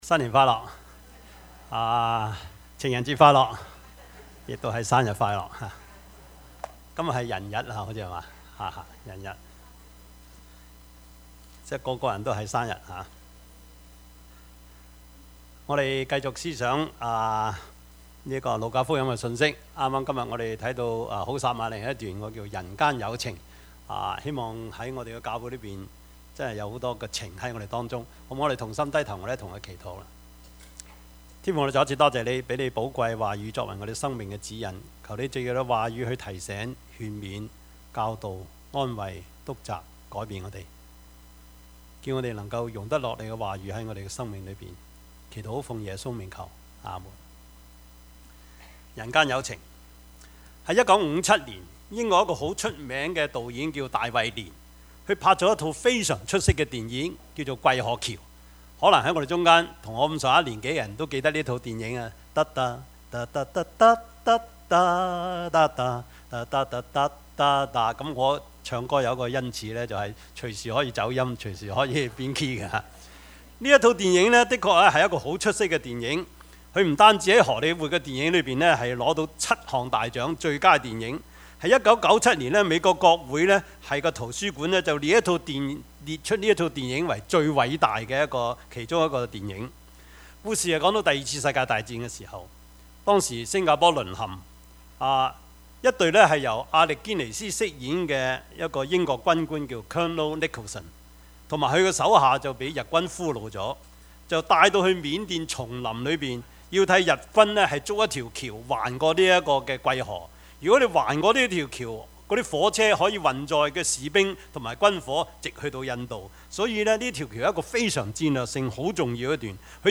Passage: 路加福音十：25-37 Service Type: 主日崇拜
Topics: 主日證道 « 豈不知你們是至幸福的嗎？